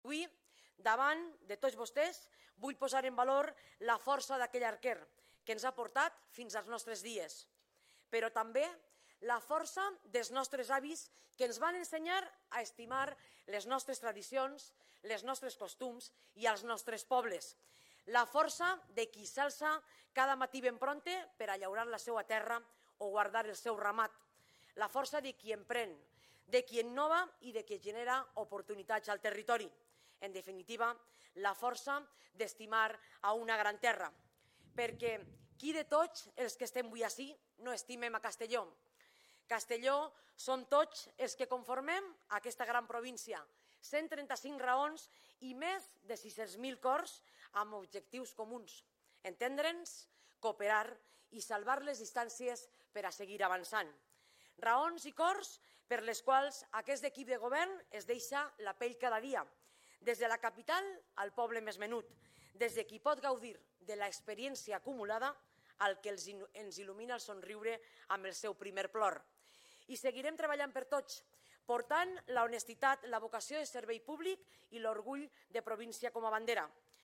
Presidenta-Marta-Barrachina-Dia-de-la-Provincia-2.mp3